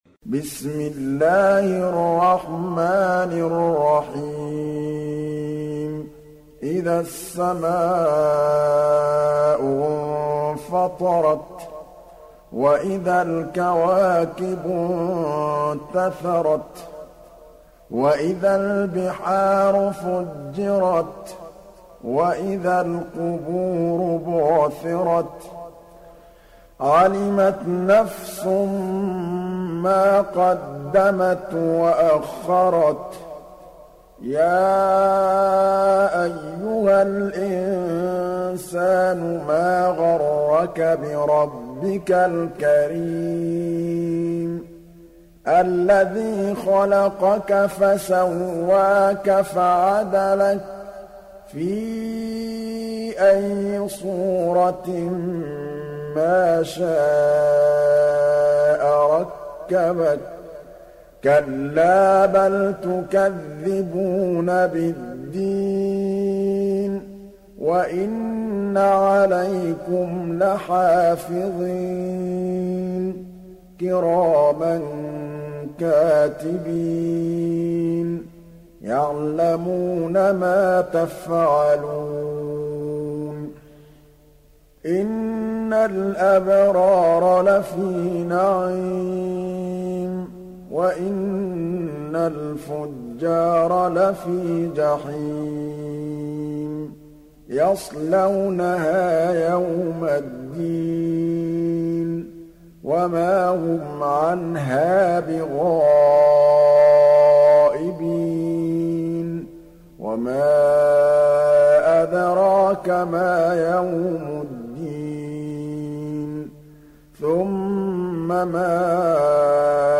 Hafs an Asim